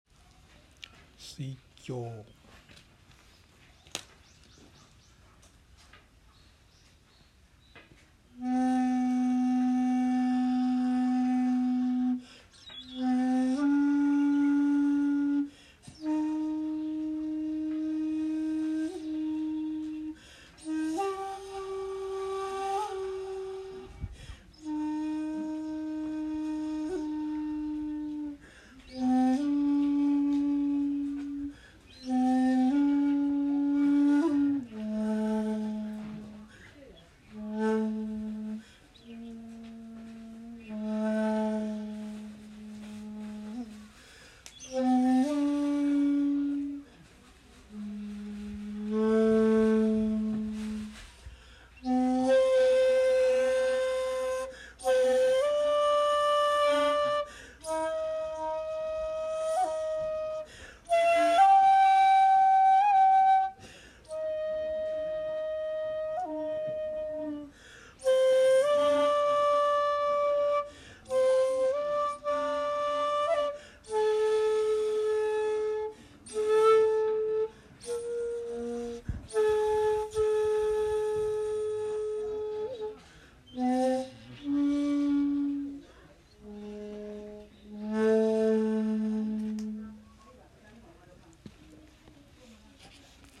今日最初の札所なので、尺八を吹奏することにしました。
（写真③：根香寺にて尺八吹奏）
（尺八音源：根香寺にて「水鏡」）